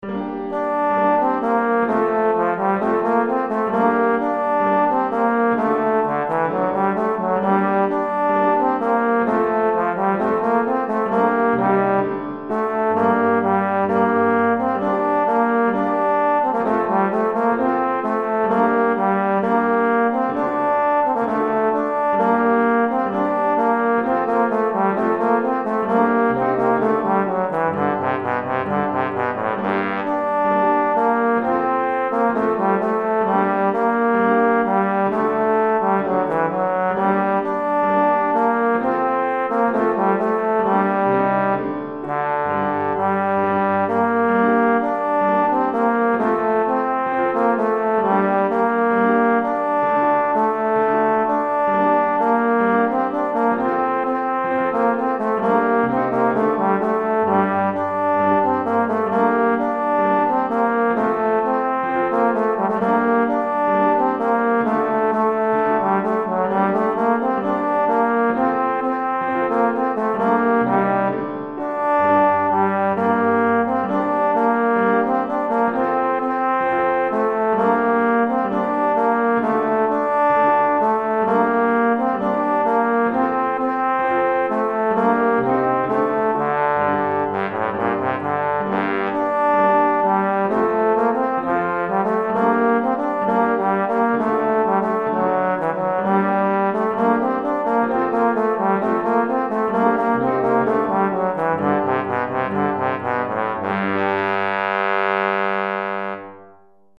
Répertoire pour Trombone - Sacqueboute et Clavecin